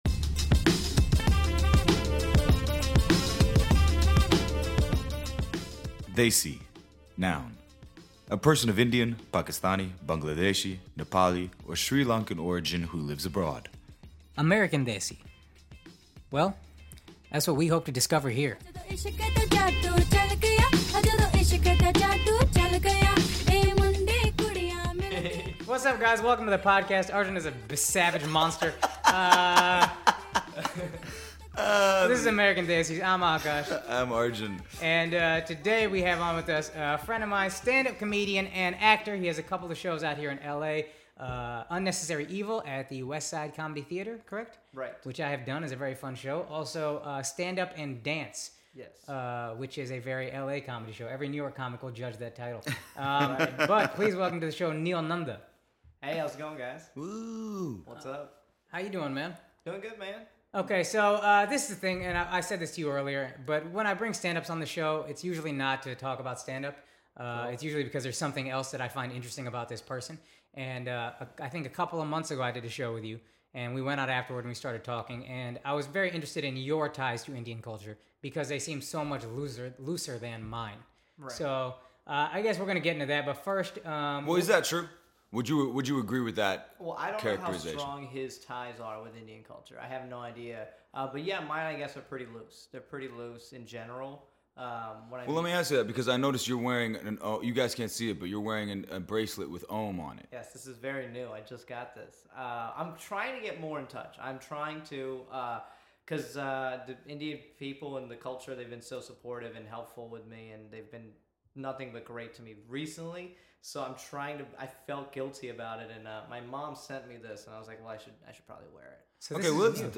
We have a raw, honest conversation with him as we put him on the therapy couch to explore where this came from.